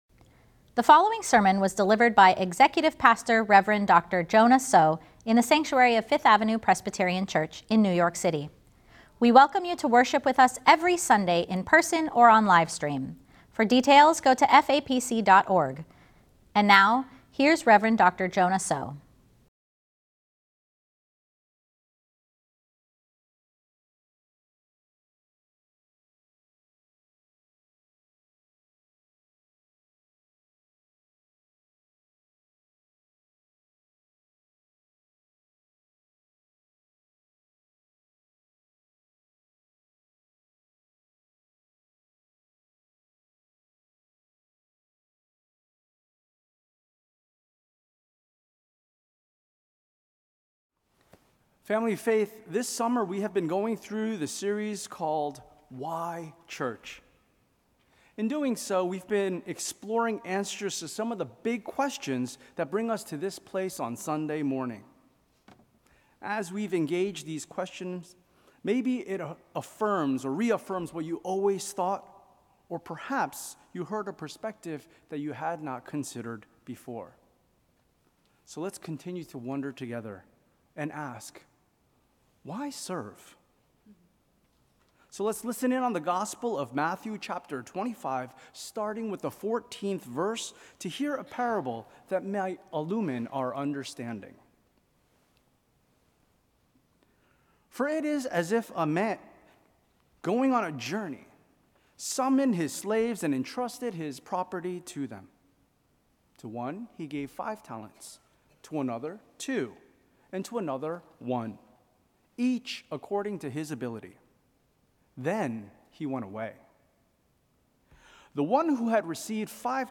Sermon: “Why Serve?”